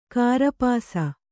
♪ kārapāsa